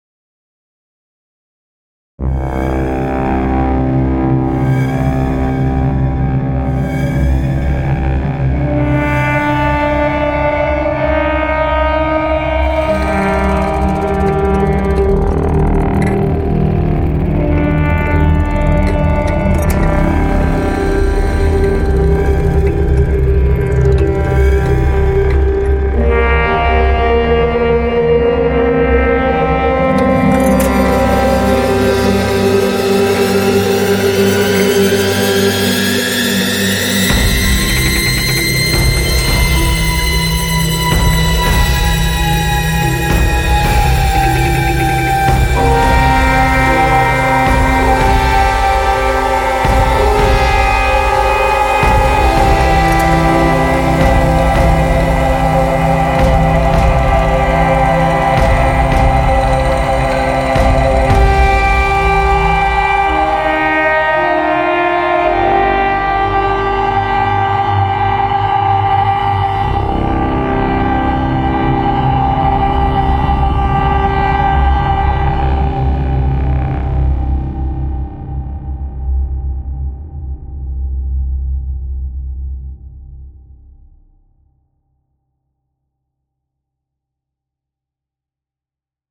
PAYSAGES SONORES SURNATURELS
• 500 instruments singuliers inspirés par les récits d’horreur lovecraftiens, la science-fiction noire et le steampunk
Acousmatic Engine associe du design sonore d’influence gothique à des contrôles de modulation de pointe pour créer des atmosphères inquiétantes, des impulsions rythmiques et des mélodies déformées.